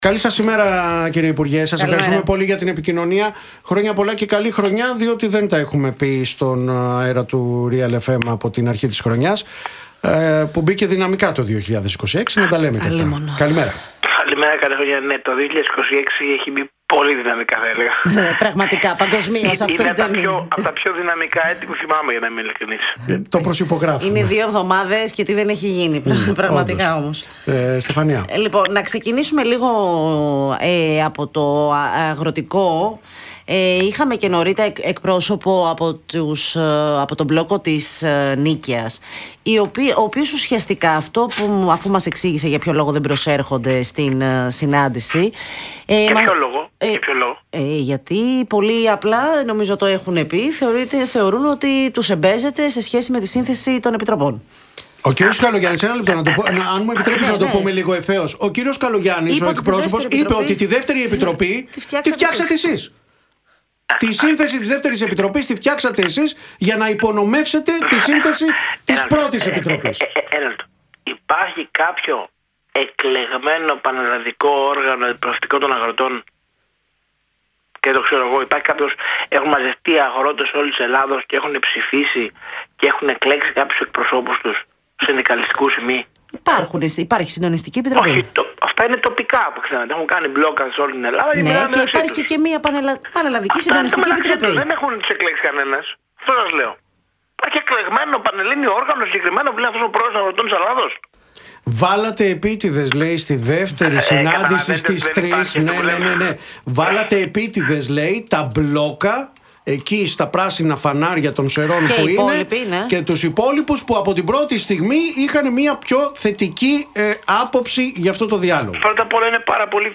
Ο αντιπρόεδρος της ΝΔ και υπουργός Υγείας, Άδωνις Γεωργιάδης μίλησε στον Realfm 97,8 μετά την άρνηση των μπλόκων της Πανελλαδικής να πάνε στη συνάντηση με τον Πρωθυπουργό, Κυριάκο Μητσοτάκη, στο Μέγαρο Μαξίμου.